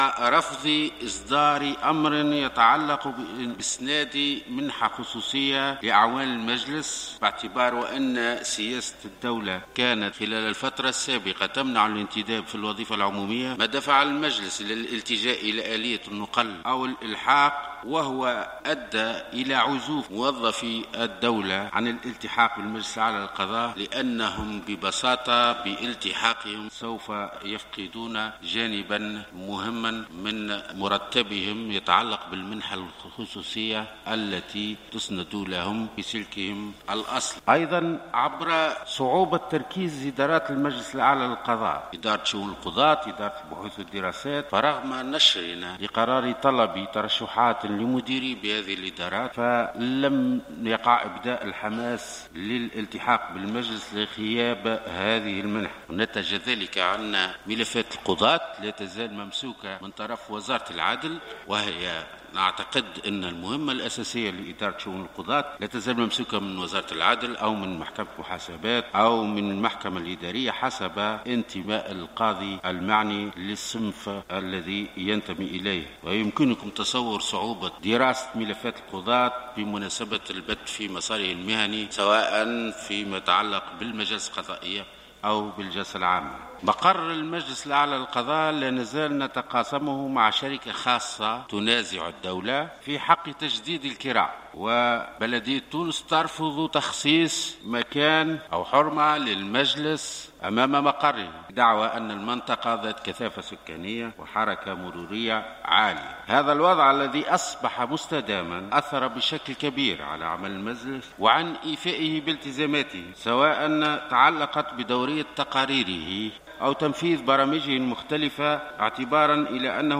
كما بين بوزاخر خلال الجلسة العامة بالبرلمان المخصصة للحوار بشأن القضاء، أنه يجب التطرق في الحوار للبناء الحقيقي للسلطة القضائية المستقلة، مشيرا الى أن الفصل 72 من قانون المجلس أوجب تخصيص الميزانية اللازمة، لعمل المجلس الأعلى للقضاء لكن على مستوى الموارد البشرية رفضت السلطة التنفيذية إلحاق اعوان للعمل كما رفضت اصدار أمر يقضي بإسناد منحة خصوصية لعملة المجلس.